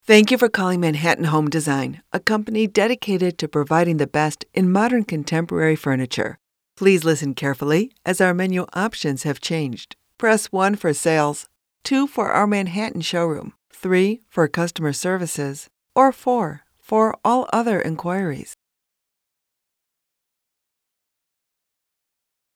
Sprechprobe: Sonstiges (Muttersprache):
My native tongue is North American English and my style is warm, trustworthy, professional, and authoritative.